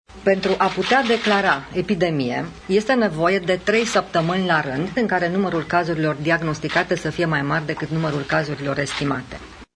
Ministrul sănătății, Sorina Pintea, spune că suntem în pragul unei epidemii, dar pentru ca aceasta să poate fi declarată trebuie întrunite anumite condiții: